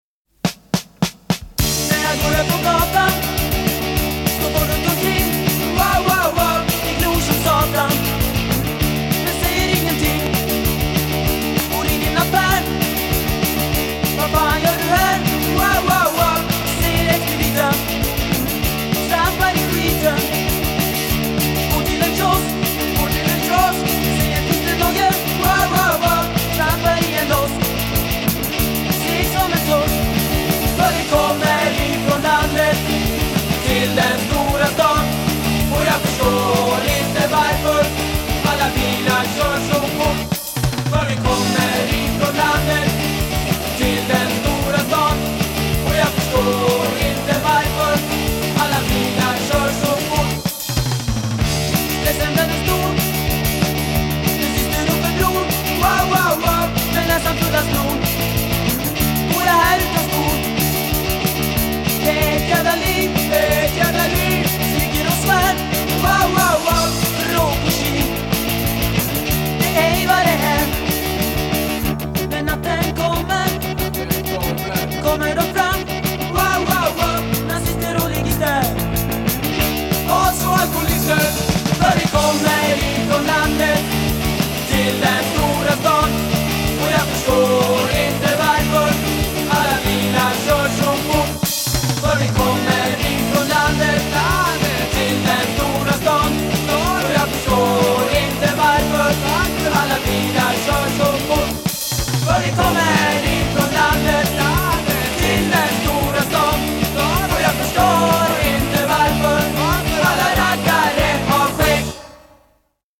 en snabb poplåt